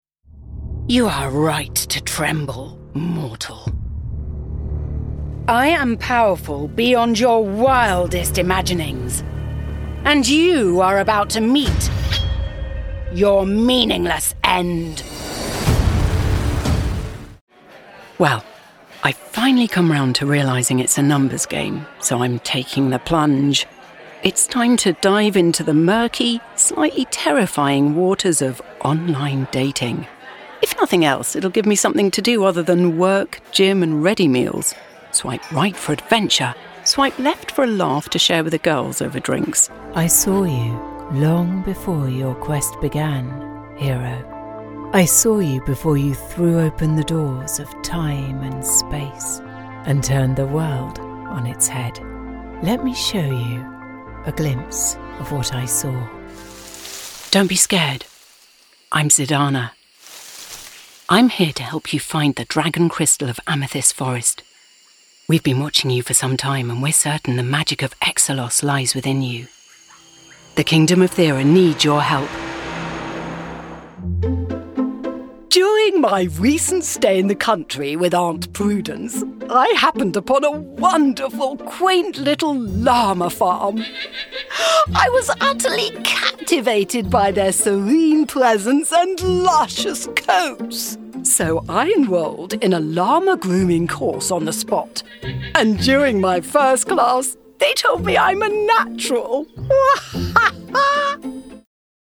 British English Female Voice Over Artist
Assured, Authoritative, Confident, Conversational, Corporate, Deep, Engaging, Friendly, Funny, Gravitas, Natural, Posh, Reassuring, Smooth, Upbeat, Versatile, Warm
Microphone: Neumann TLM 103
Audio equipment: Focusrite clarett 2 PRE, Mac, fully sound-proofed home studio